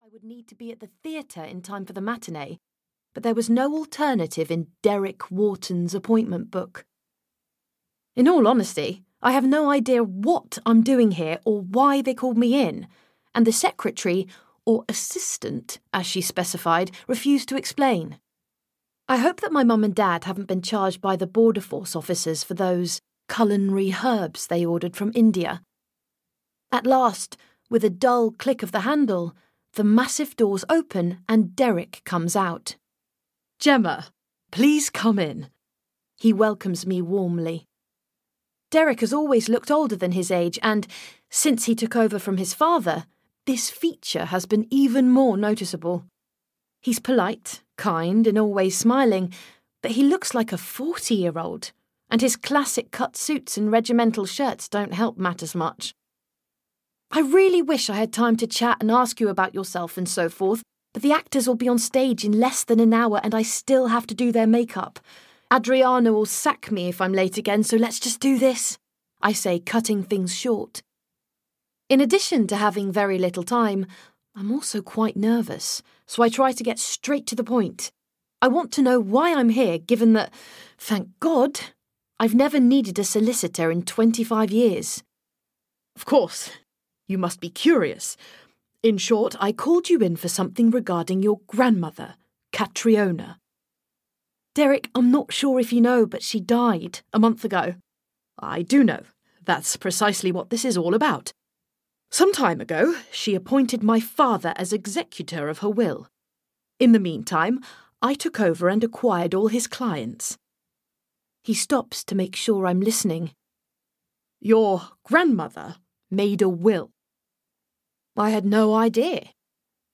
Audio knihaHow (Not) to Marry a Duke (EN)
Ukázka z knihy